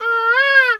bird_peacock_squawk_06.wav